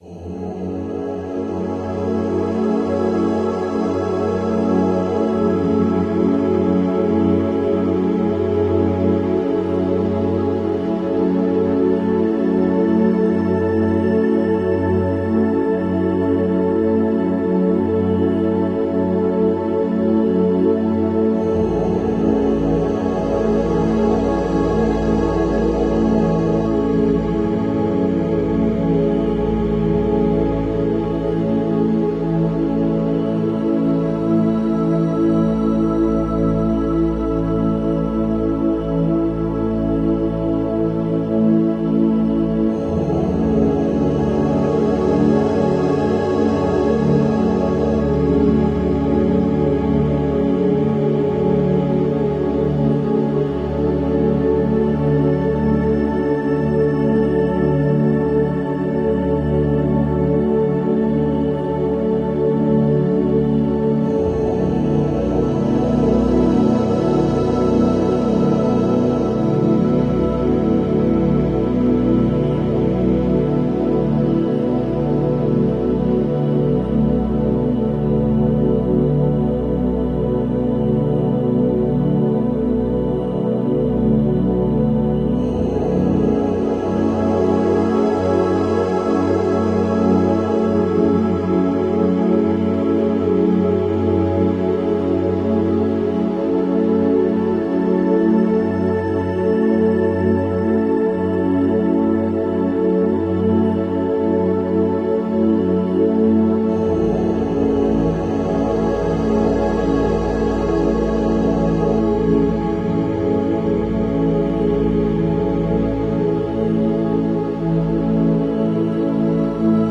In this meditation, a monk sits in deep contemplation, harmonizing with the eternal vibration of OM—the primordial sound of the Universe.
🔔 The Power of the Japanese Temple Bell As the bell’s deep, pure tones echo across the silence, its frequency cuts through illusion, grounding the soul while lifting the spirit.
In this short meditation, the combined frequencies of OM and the Temple bell open a gateway to inner peace and higher awareness.